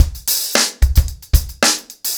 TupidCow-110BPM.41.wav